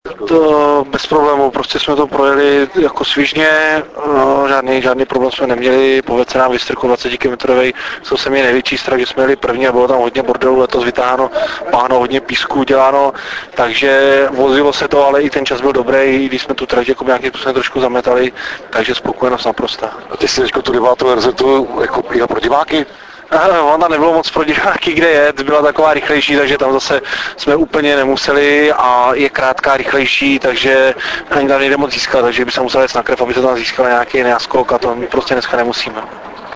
Rozhovory v MP3 po RZ 9: